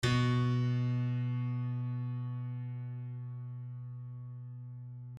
piano-sounds-dev
LoudAndProudPiano